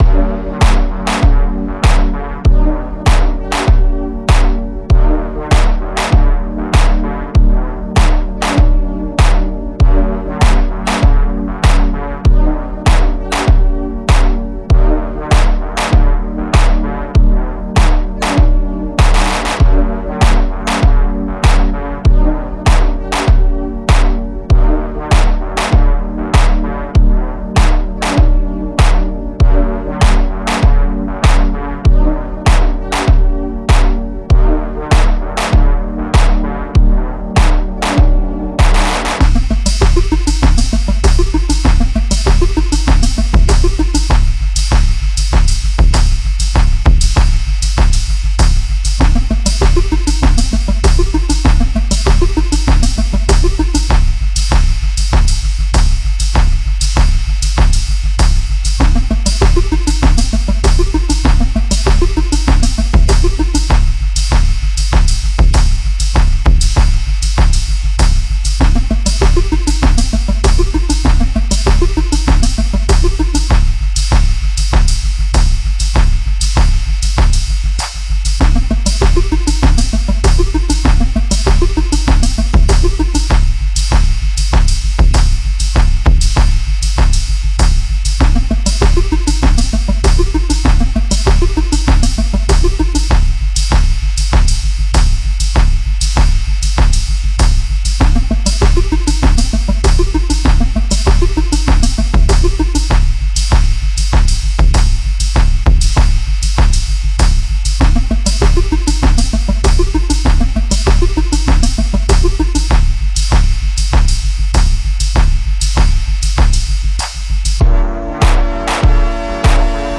Танцевальная Музыка, Дискотека 90 Х, Дискотека 80 Х -